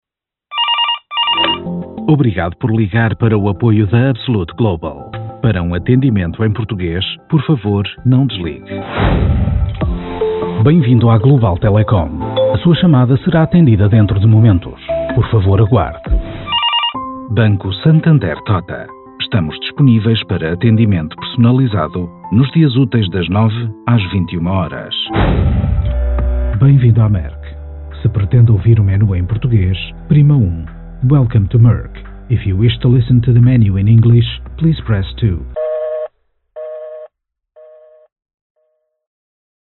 Portuguese Professional Voice Actor.
Sprechprobe: Sonstiges (Muttersprache):
Baritone male voice with pleasant, warm, calm, smooth and vibrant tone.
IVR PT demo.mp3